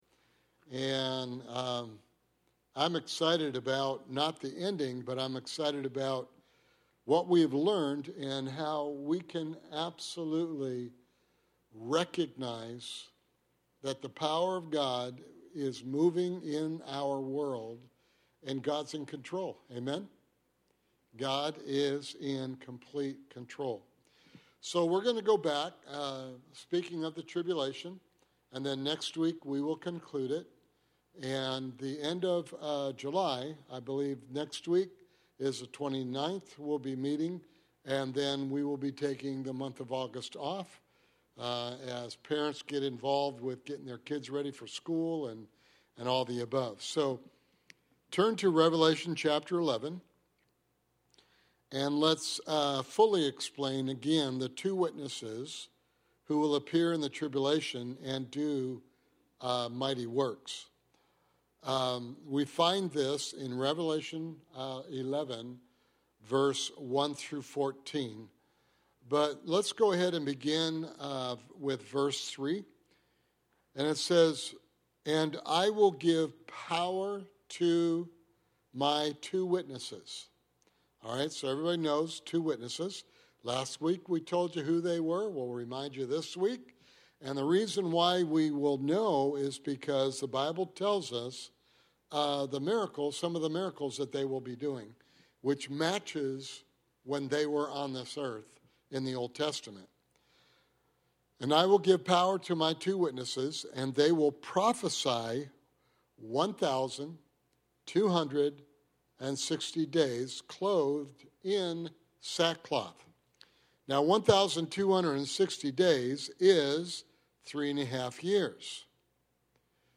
Sermon Series: Understanding the End Times